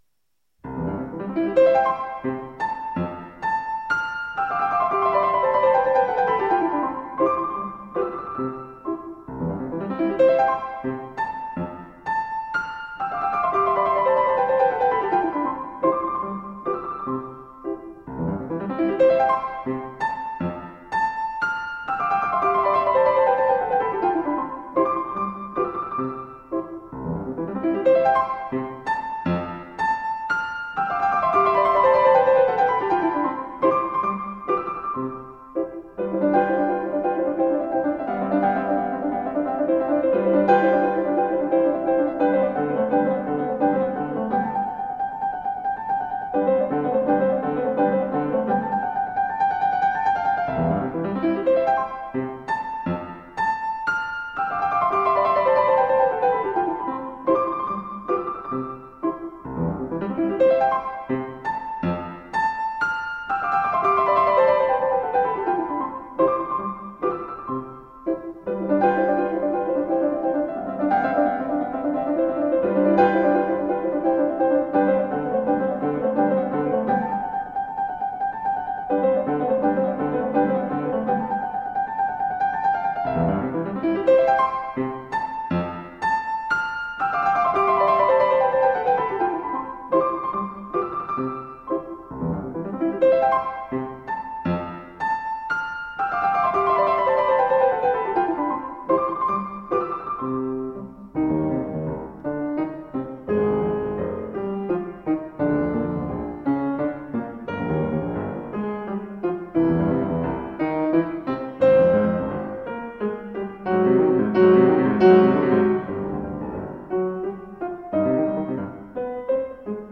Classical works from a world renowned pianist.
A bagatelle is a kind of brief character piece.